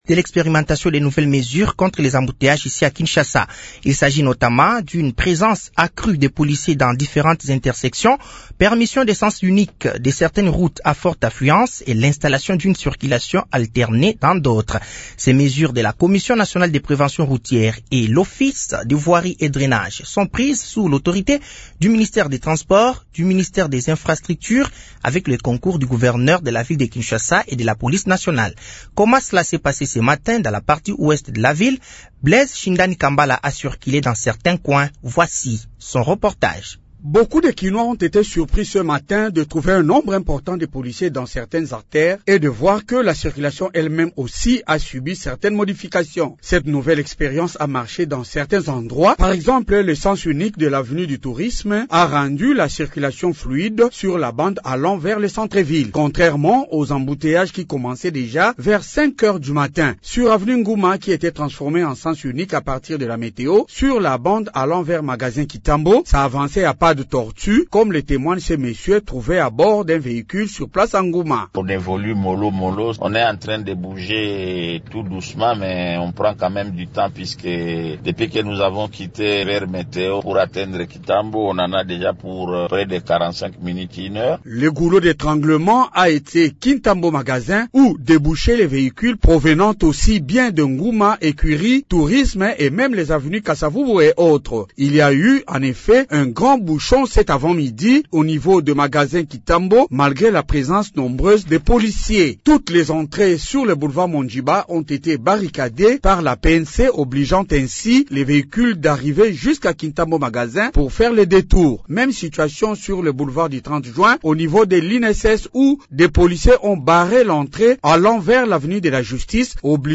Journal français de 18h de ce lundi 28 octobre 2024